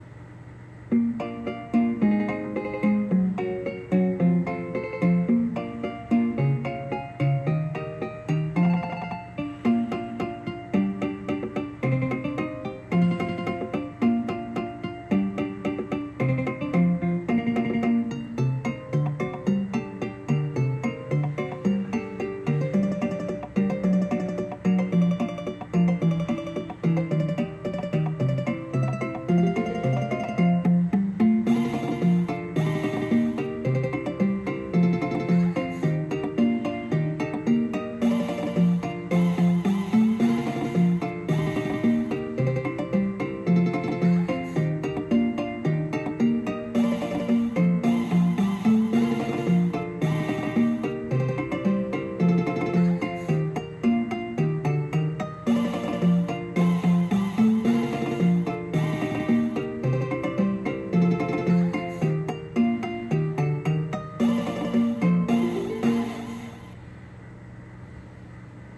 Ukulele Mix